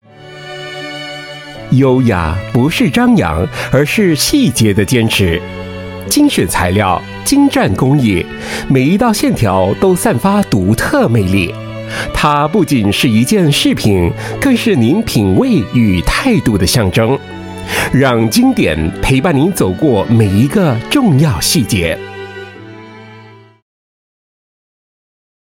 Voice Samples: Luxury
male